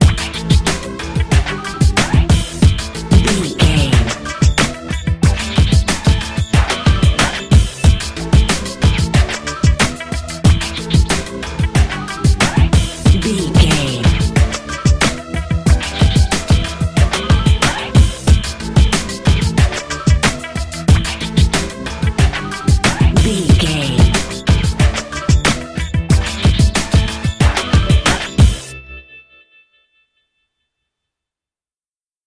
Aeolian/Minor
drum machine
synthesiser
percussion
neo soul
acid jazz
energetic
bouncy
hard hitting